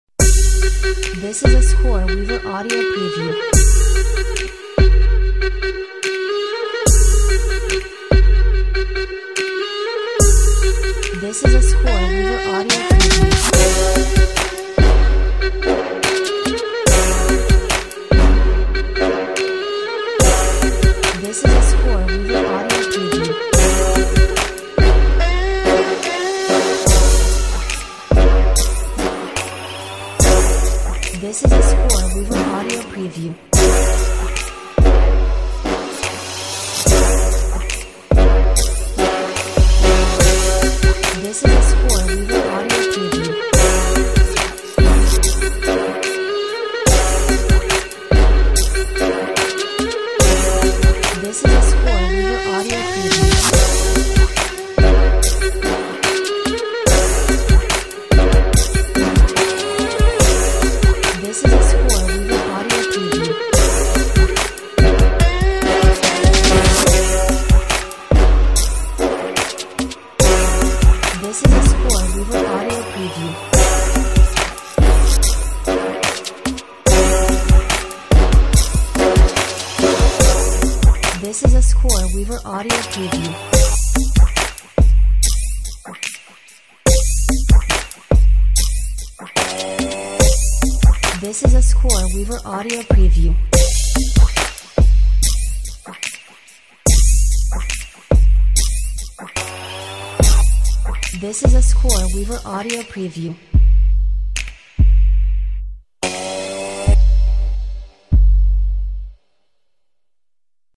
Sizzling hot Hip Hop / Trap Step with detuned lead synths!